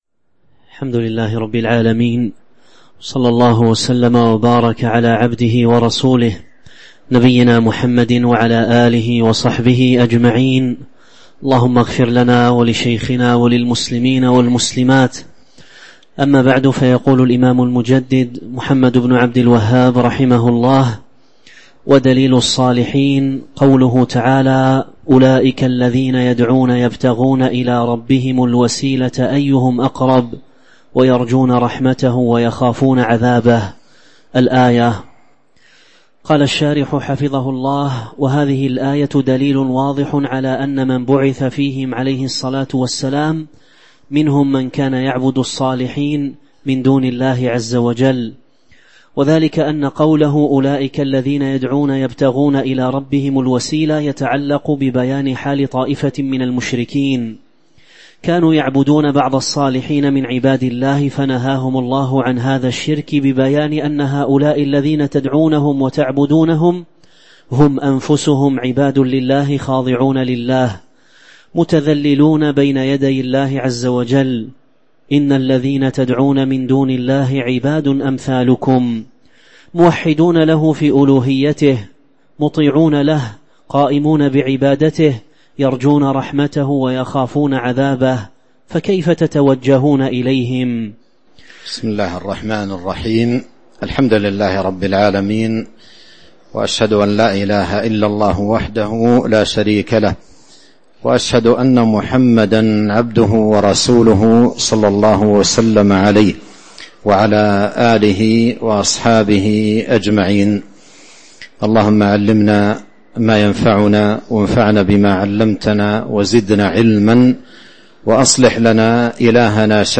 تاريخ النشر ٢٧ شوال ١٤٤٤ هـ المكان: المسجد النبوي الشيخ: فضيلة الشيخ عبد الرزاق بن عبد المحسن البدر فضيلة الشيخ عبد الرزاق بن عبد المحسن البدر قوله: ودليل الصالحين (04)